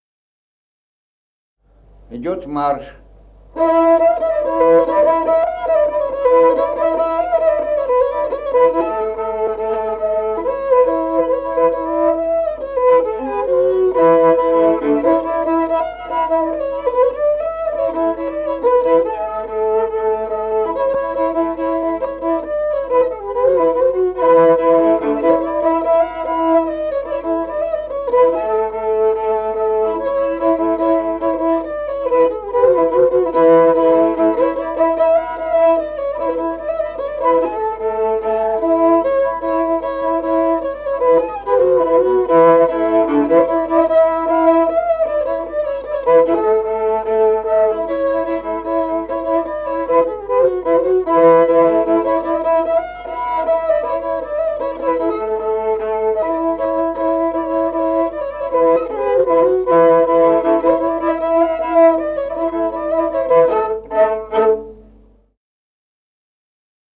Музыкальный фольклор села Мишковка «Марш», репертуар скрипача.